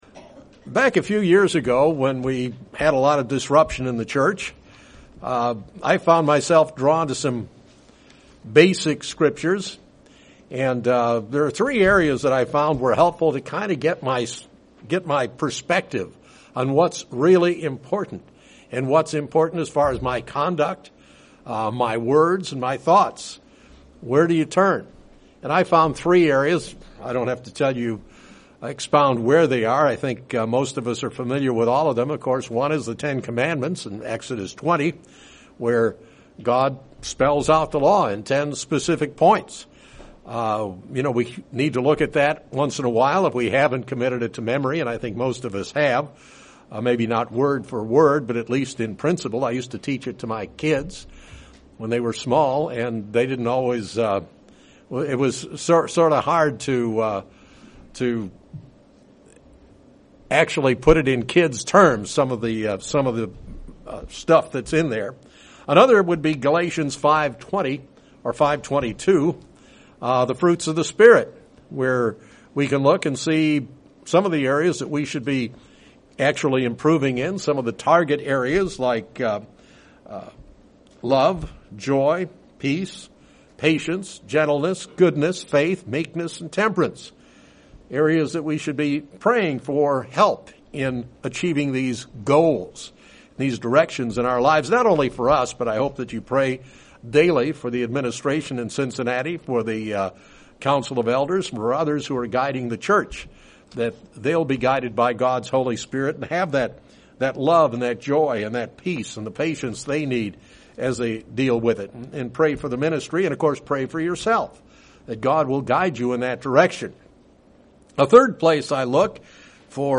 As Chrisitans we should seek purity. This sermon looks into the topic of purity as found in the bible.